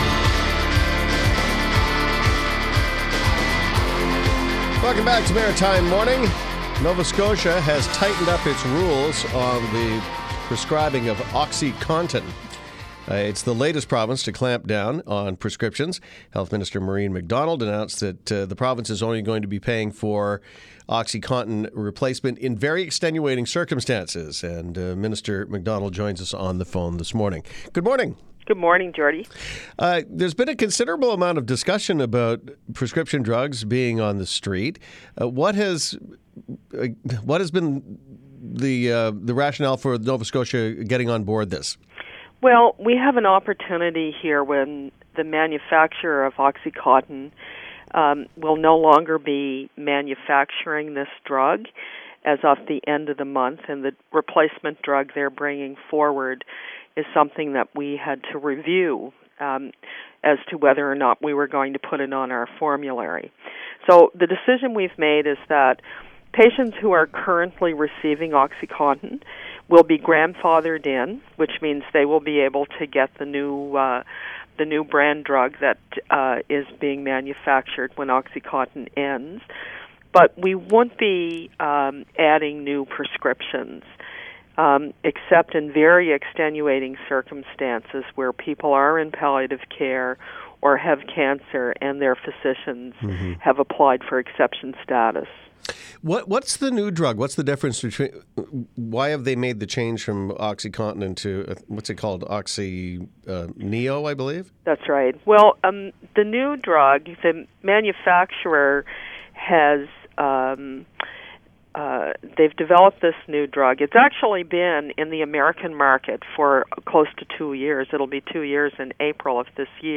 This is a great interview, positive approach to go along with all the other provinces of Canada. i still believe it has the potential to be abused as it is still addictive, so if it hits the streets, it may not beable to be crushed, it will still cause harm.